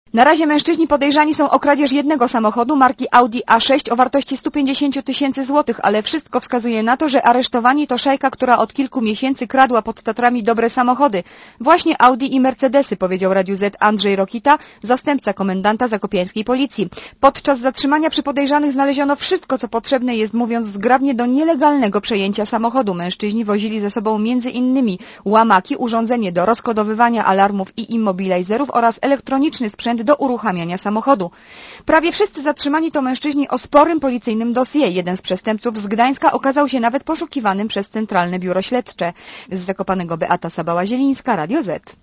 Posłuchaj relacji reporterki Radia Zet (160 Kb)